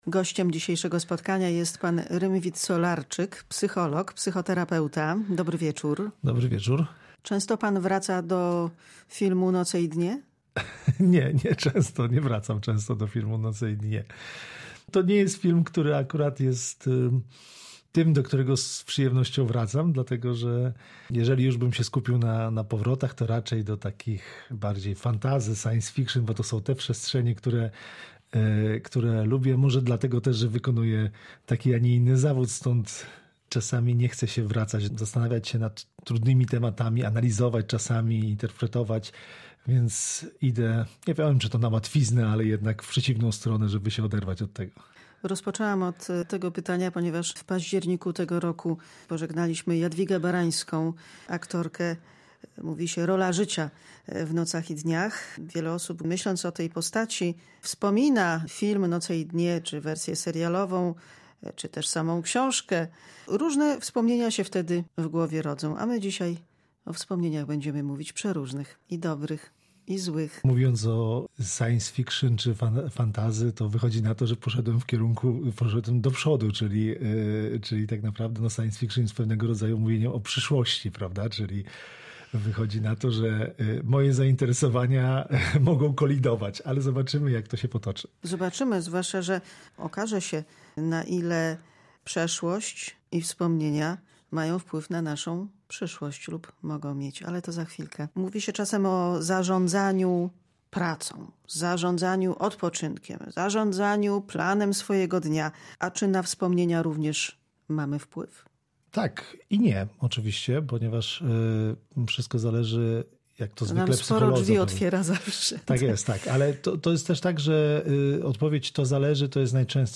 Te i inne tematy w rozmowie z gościem programu Pisma i znaki.